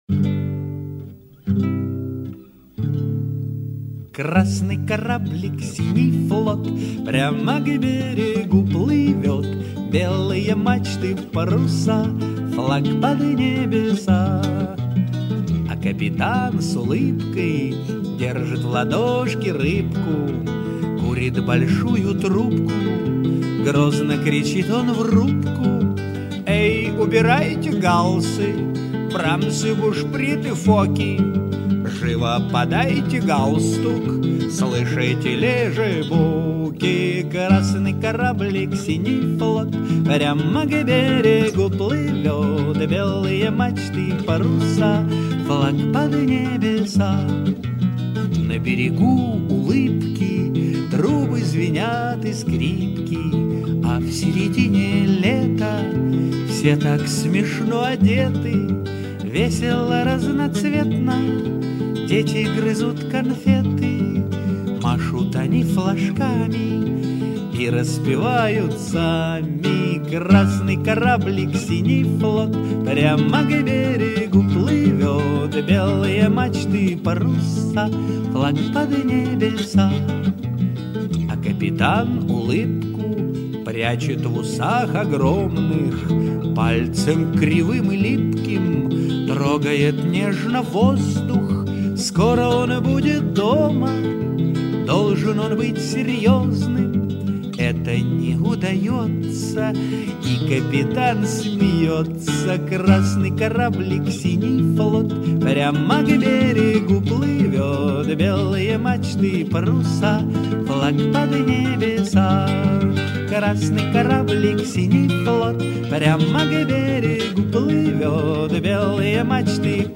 В исполнении автора